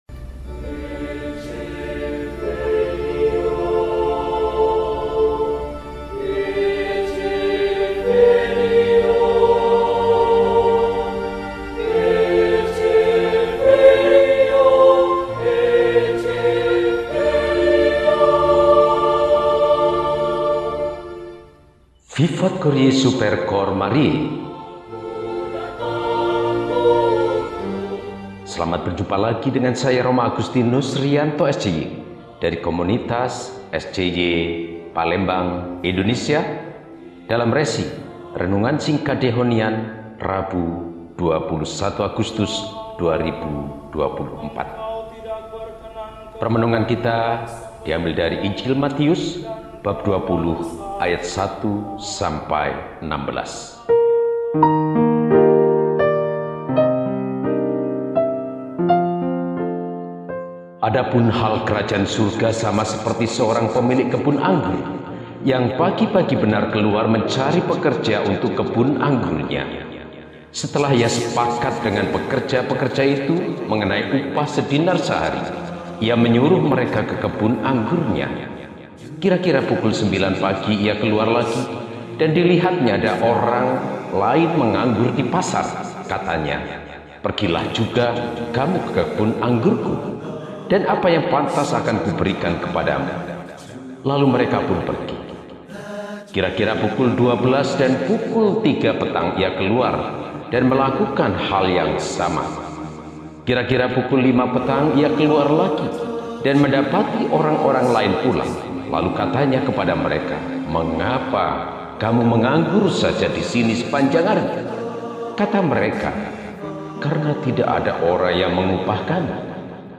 Rabu, 21 Agustus 2024 – Peringatan St. Pius X, Paus – RESI (Renungan Singkat) DEHONIAN